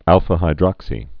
(ălfə-hī-drŏksē)